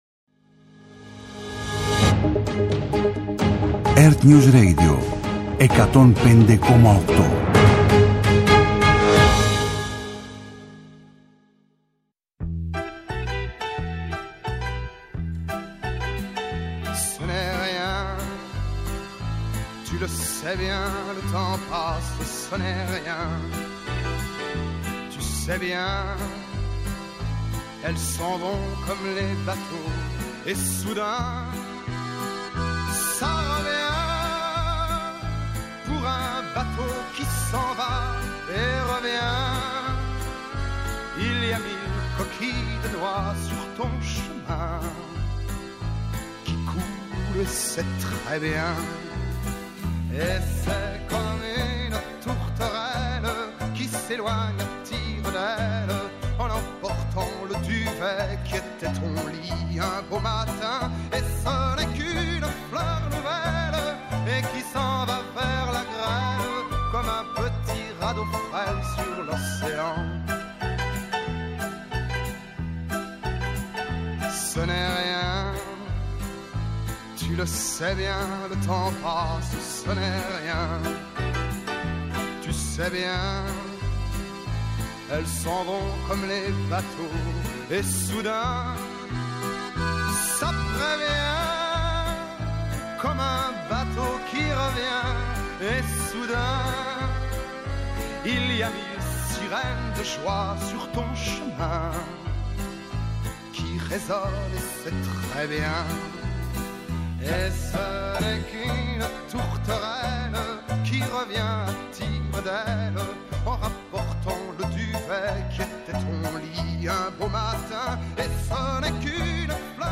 Συζήτηση με τους πρωταγωνιστές των γεγονότων.